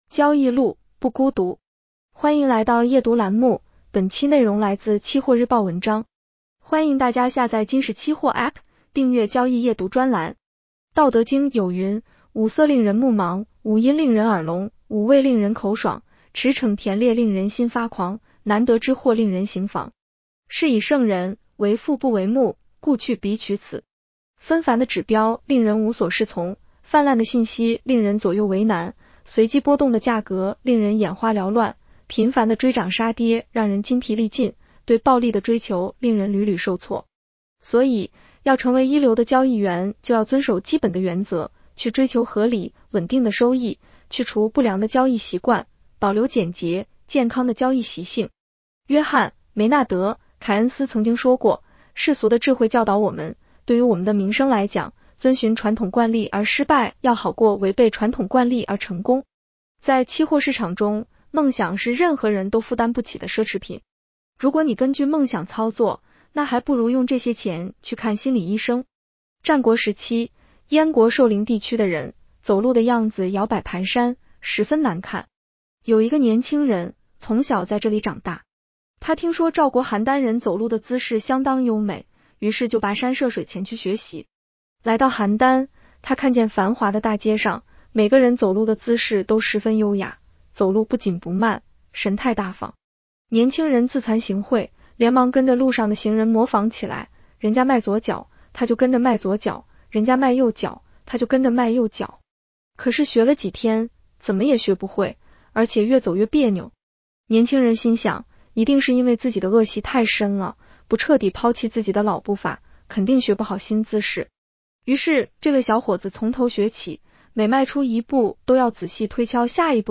【期货交易夜读音频版】
女声普通话版 下载mp3